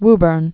(wbərn)